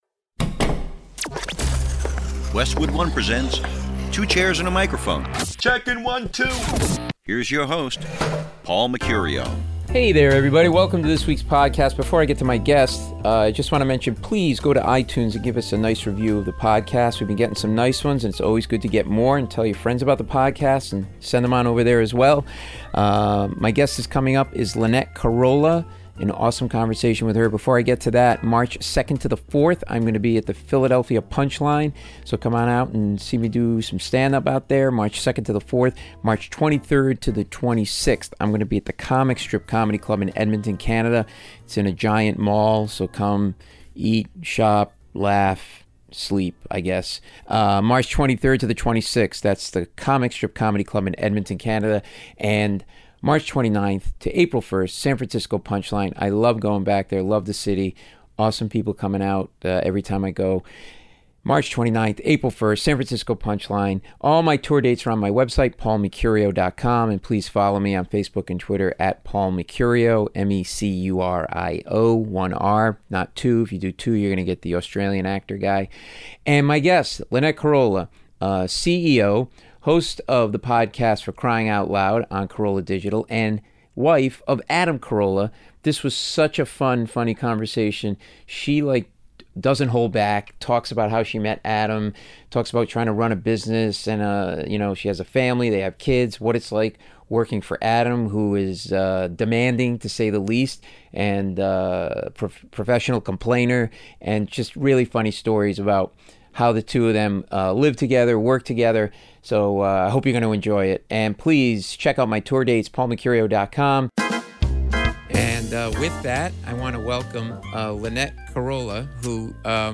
This is a really funny conversation